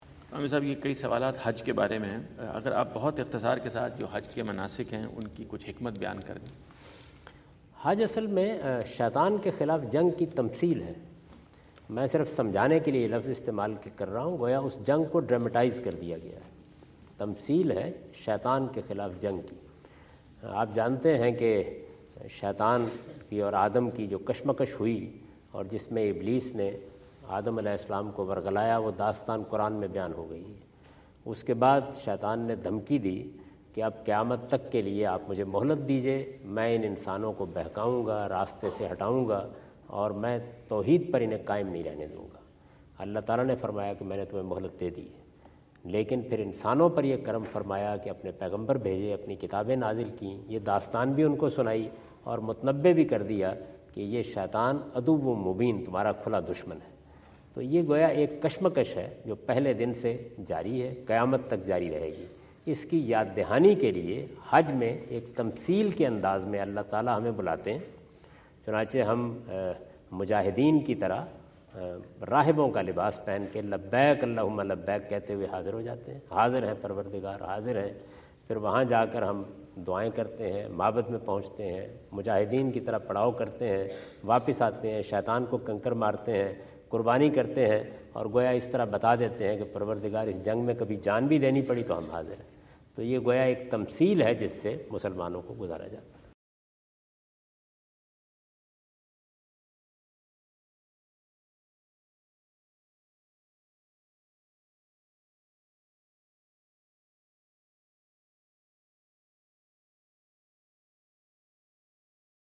In this video Javed Ahmad Ghamidi answer the question about "wisdom behind hajj rituals" asked at St.John’s University Auditorium New York on September 30,2017.